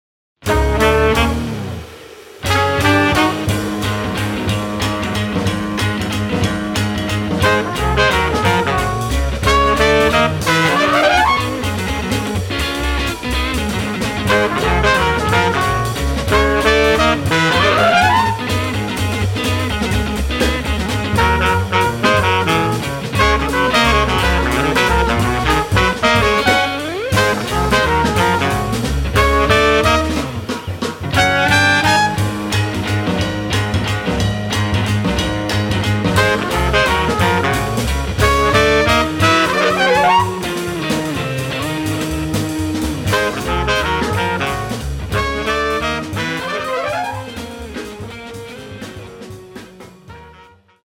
trumpet
sax
bass
drums
guitar